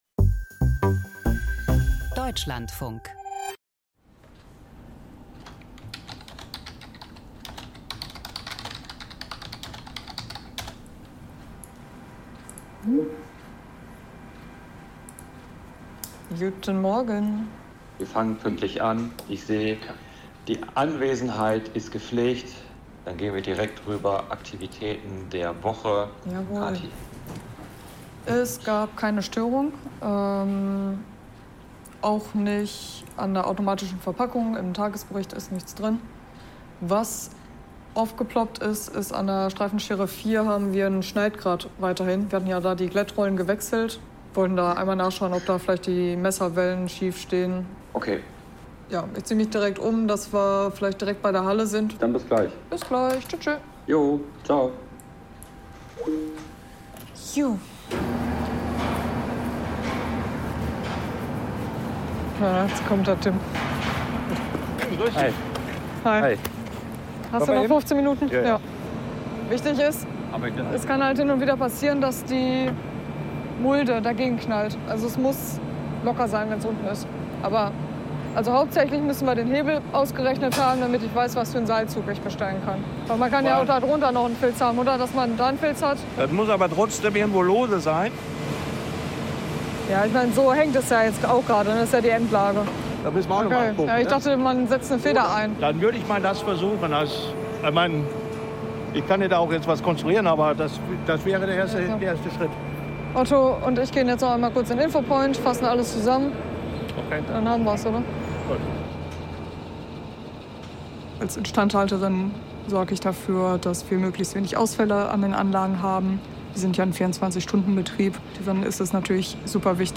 a> Feature